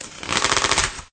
tear_longer_rippling_1.ogg